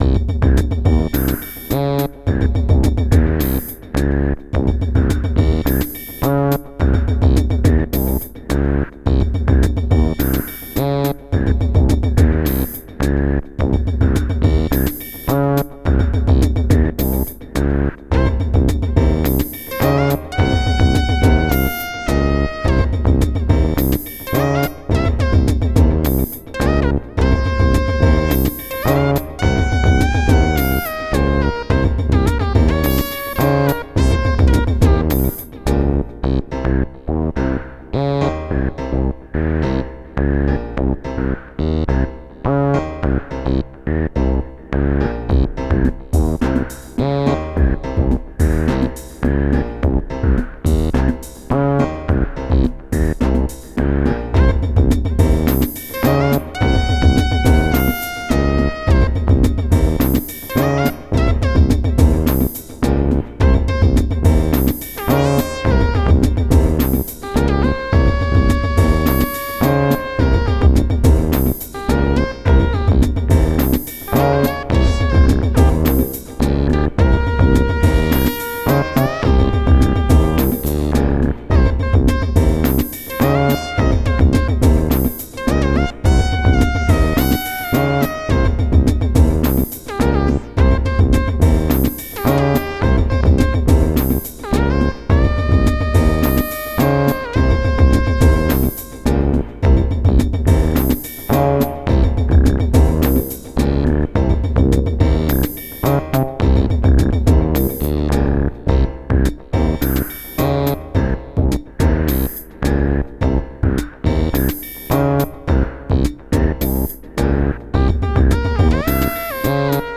Pieza de Electro-Jazz
Música electrónica
jazz
melodía
rítmico
sintetizador